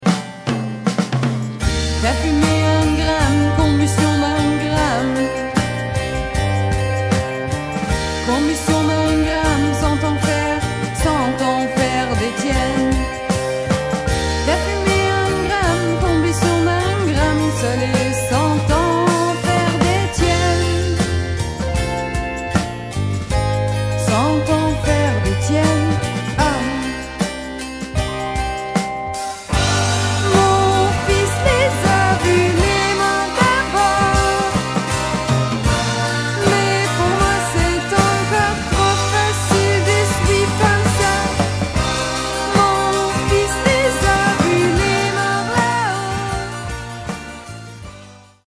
französischen Sixties-Beat, charmante Pop-Songs